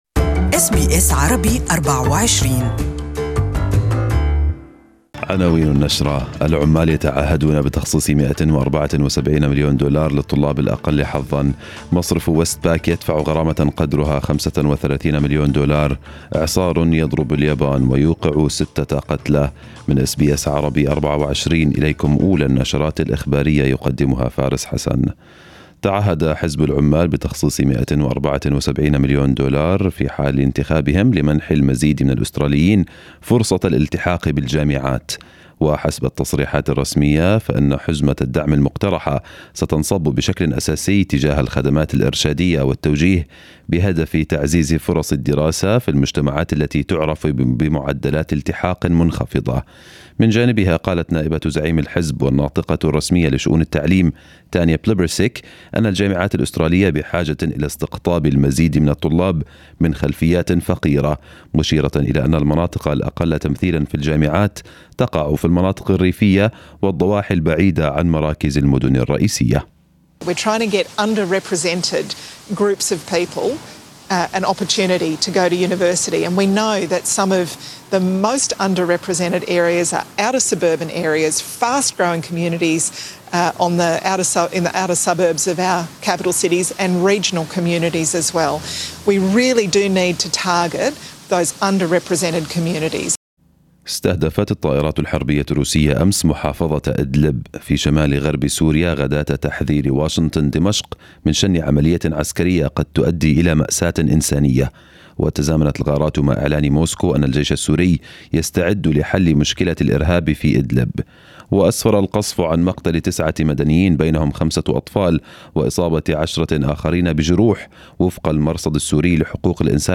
Listen to the full news bulletin in Arabic above.